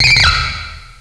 pokeemerald / sound / direct_sound_samples / cries / scraggy.aif